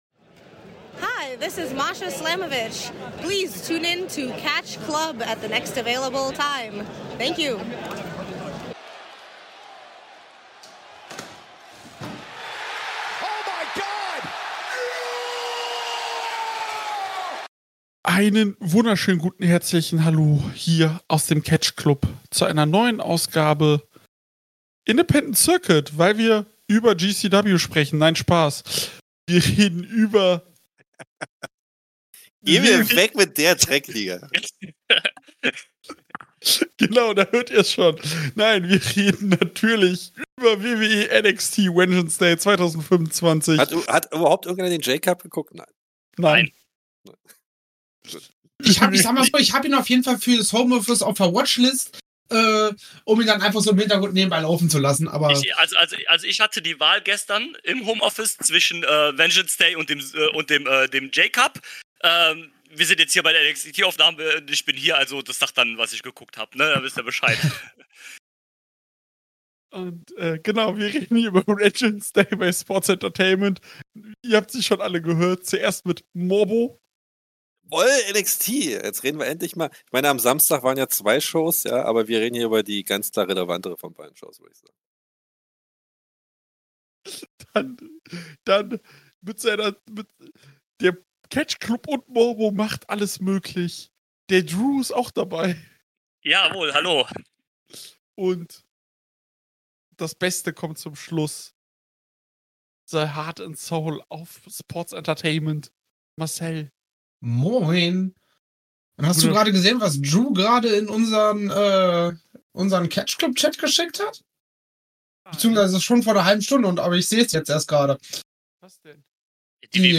Der erste PLE des Jahres von NXT stand an. In der großen 4er Runde wird über Vengeance Day gesprochen.